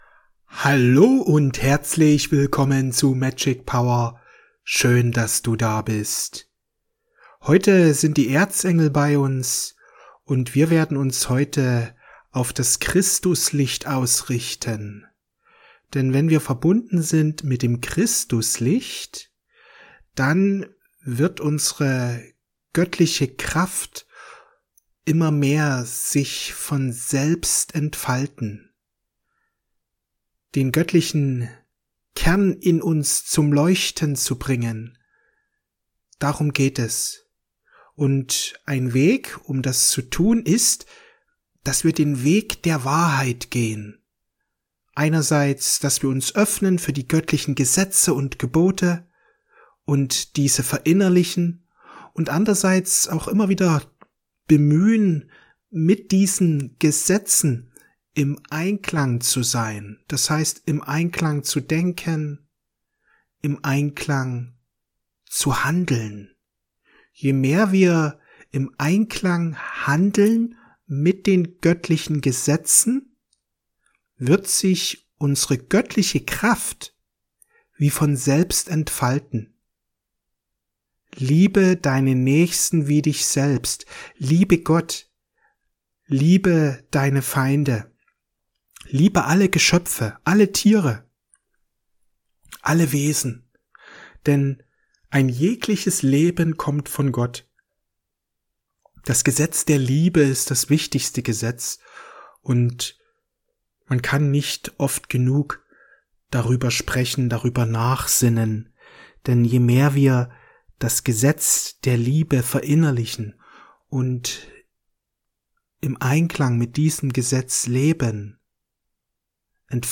Meditation mit dem blau-goldenen Licht